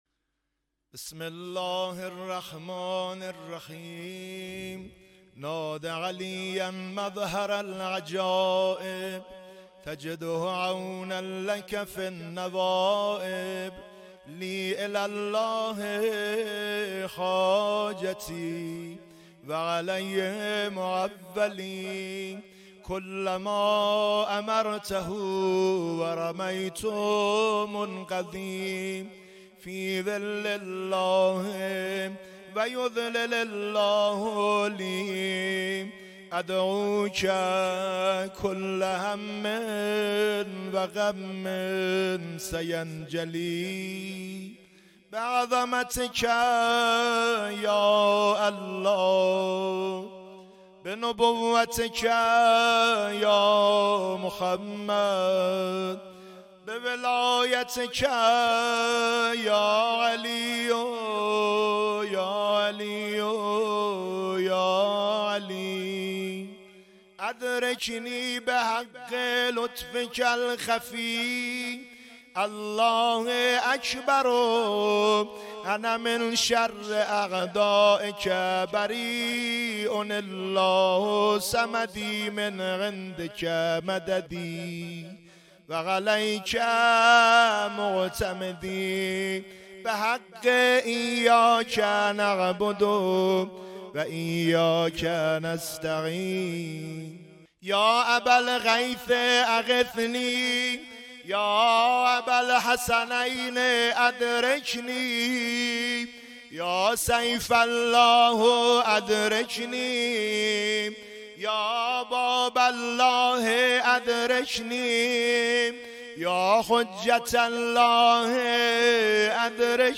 دعای نادعلی صوتی